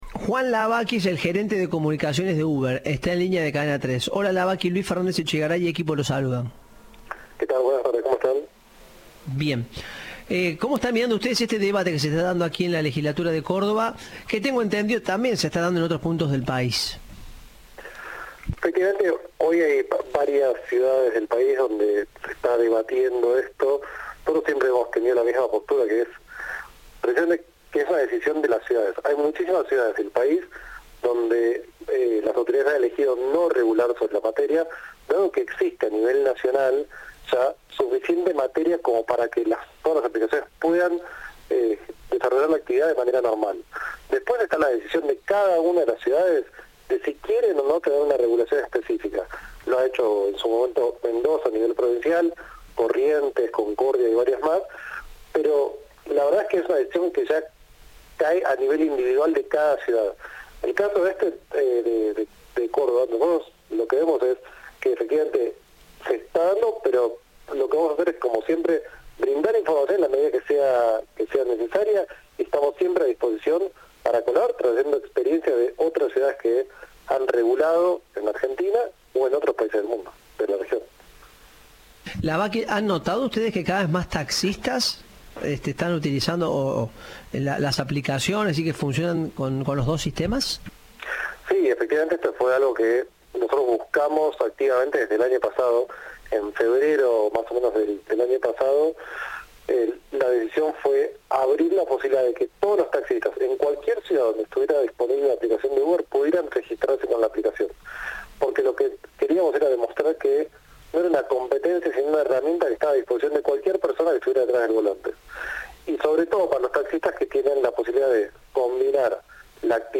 Entrevista de “Informados al Regreso”.